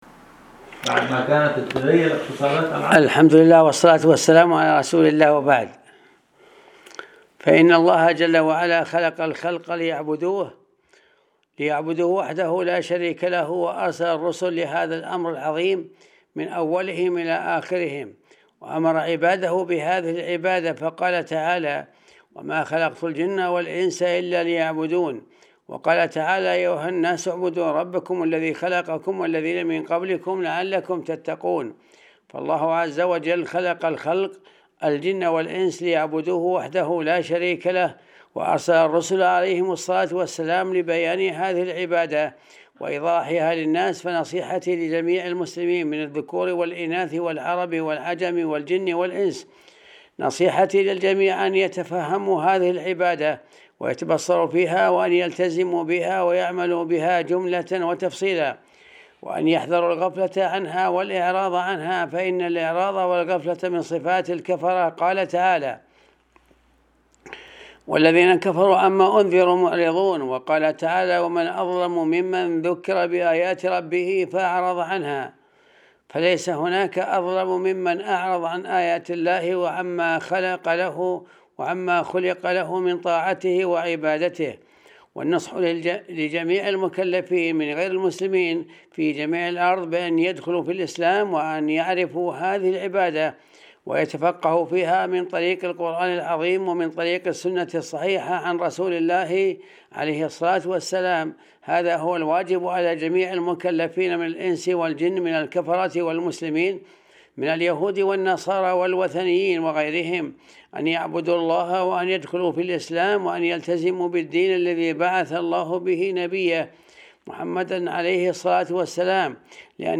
الدرس -265-ج-5-نصيحة-هامة-إلى-عامة-الأمة.mp3